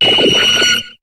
Cri de Lewsor dans Pokémon HOME.